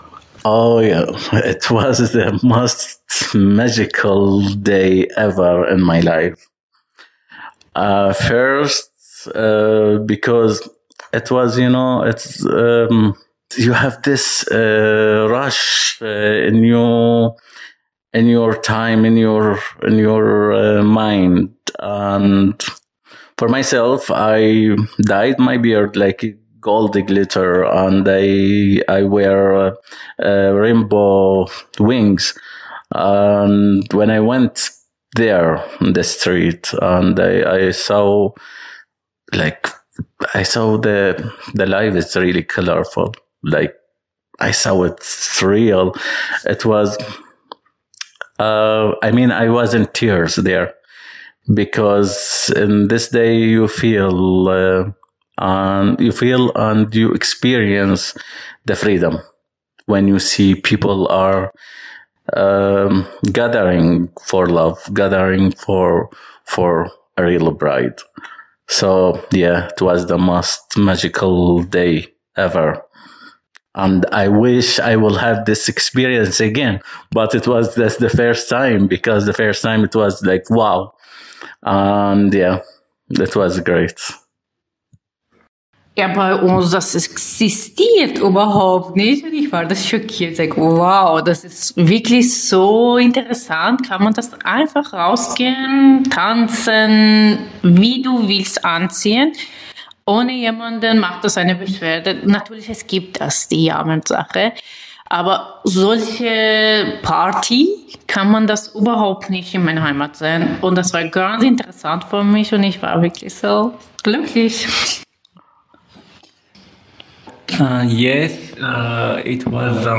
Oral History: Die Geschichte queerer Migration und Flucht ist geprägt von dem Streben nach Freiheit und Selbstbestimmung.